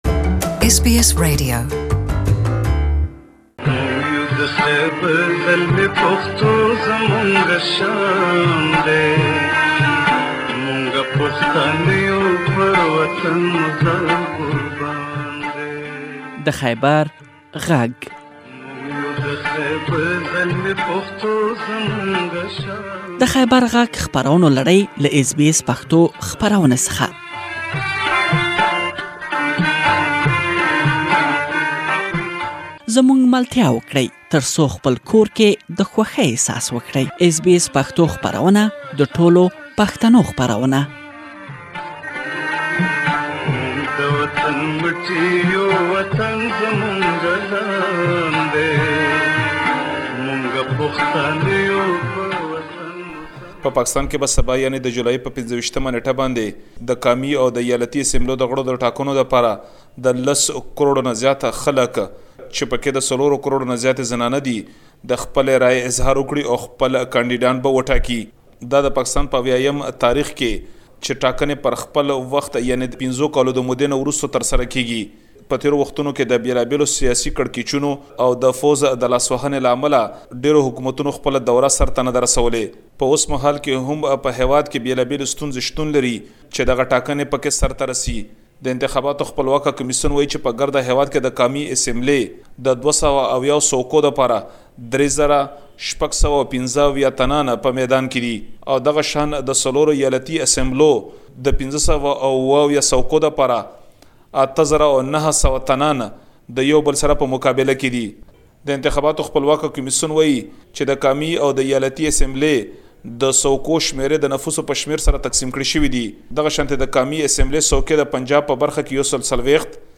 For more details, Please listen to the full report about the elections of Pakistan.